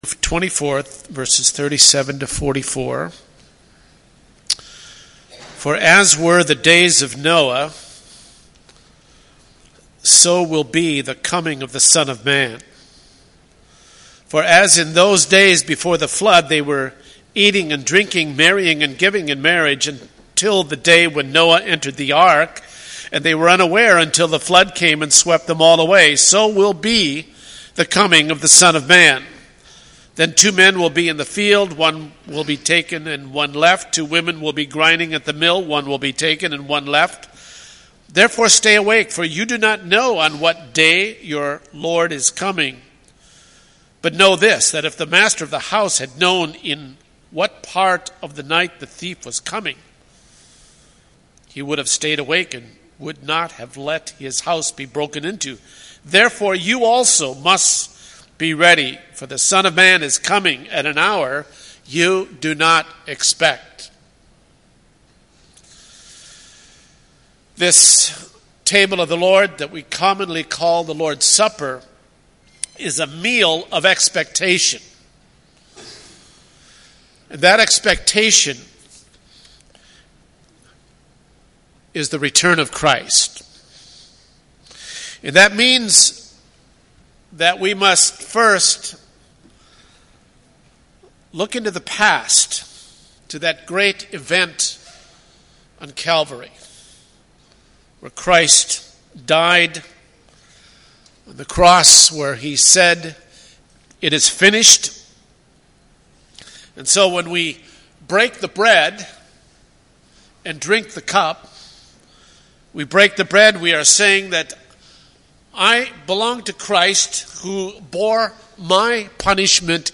Communion Homily: Remembering Christ’s Return
Service Type: Sunday Morning